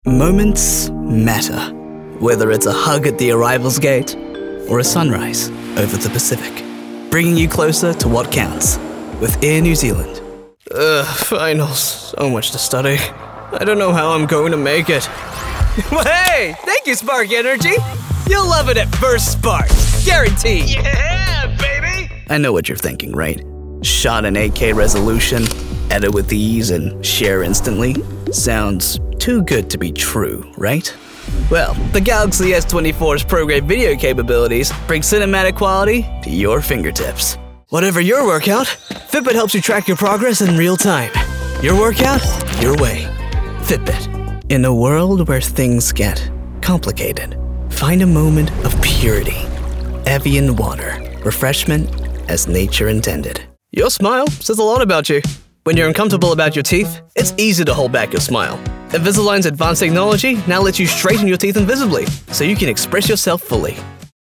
COMMERCIAL 💸